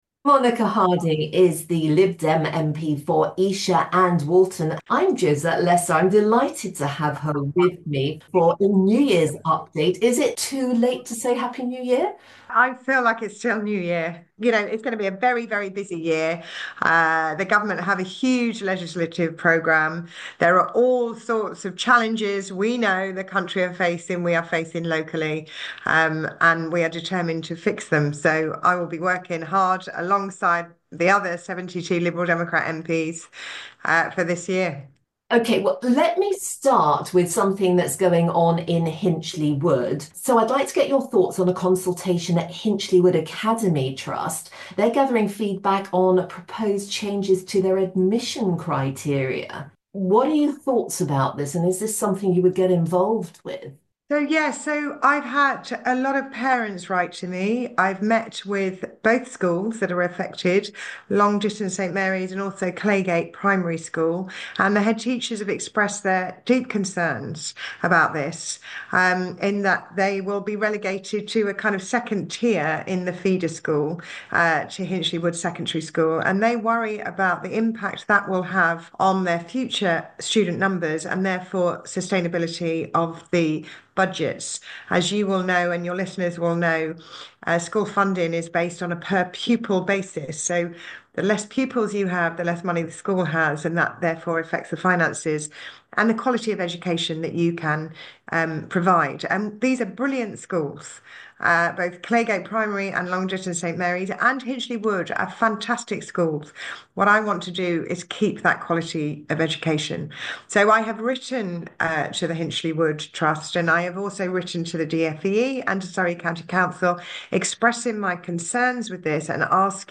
interviews Lib Dem MP for Esher and Walton, Monica Harding.